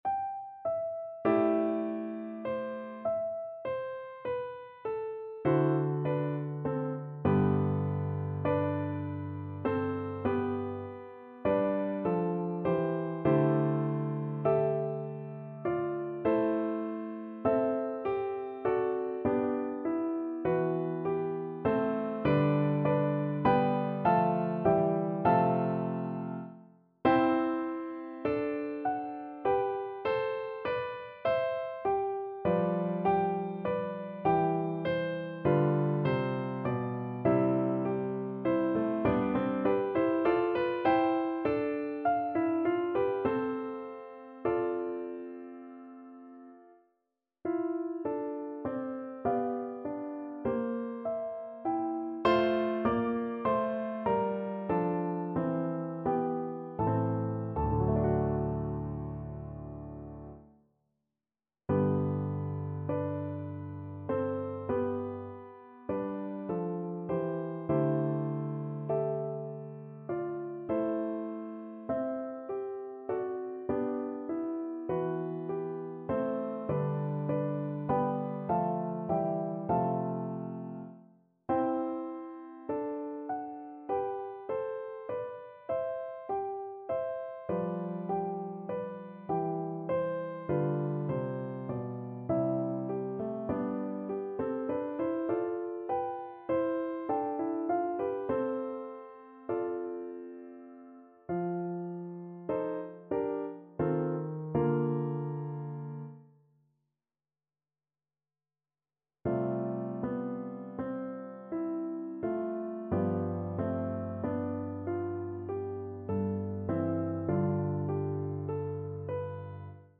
Classical (View more Classical Violin Music)